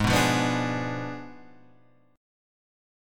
G#dim7 chord {4 5 6 4 6 4} chord